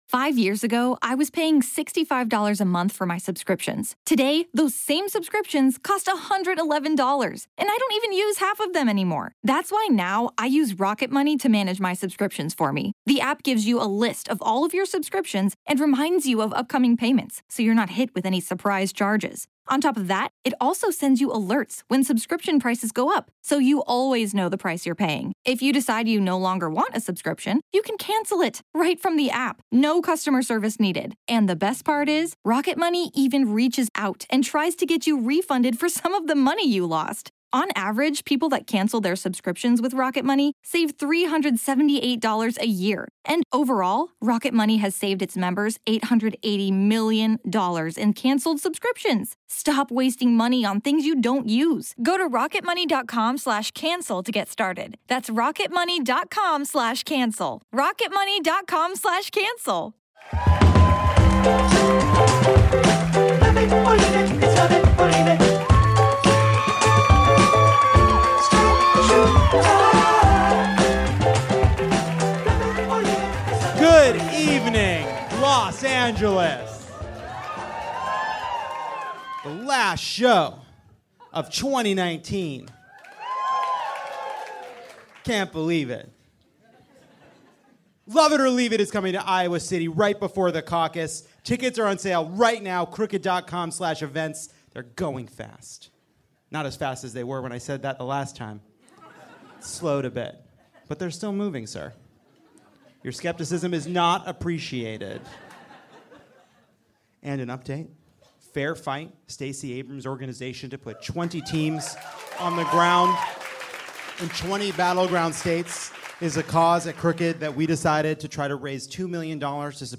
Mayor Pete Buttigieg joins Jon in studio to play Queen for a Day. Should college plans be universal?
Plus Josh Gondelman and Negin Farsad join live as Trump gets impeached, the Democrats get feisty at the debate, and Kumail Nanjiani gets jacked.